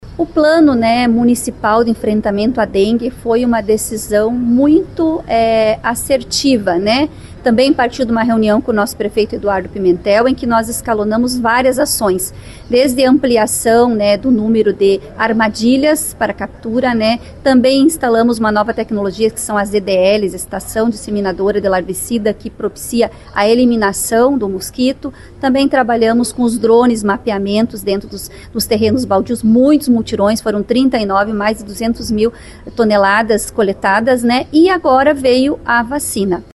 A informação foi divulgada por meio da responsável pela pasta, Tatiane Filipak, durante a audiência de prestação de contas da Saúde relativa ao primeiro quadrimestre deste ano. Ela falou sobre o cenário da imunização e a respeito dos casos da doença na cidade.